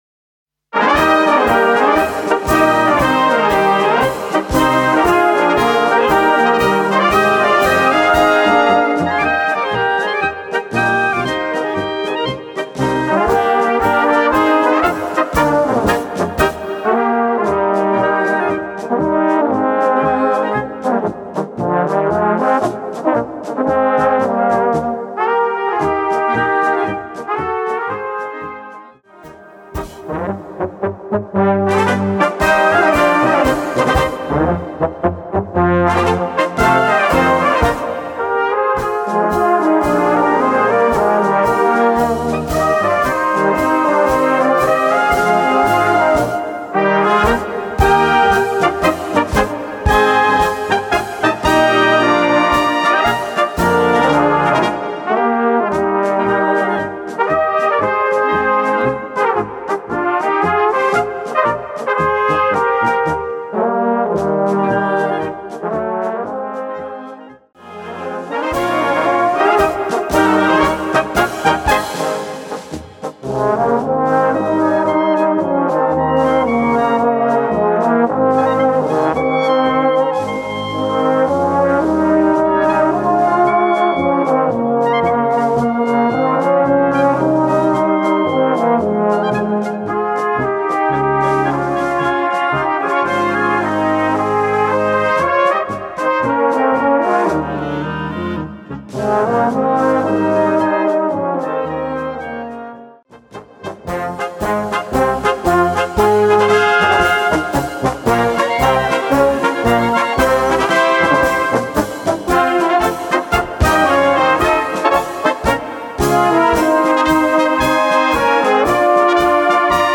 Gattung: Konzertmarsch für Blasorchester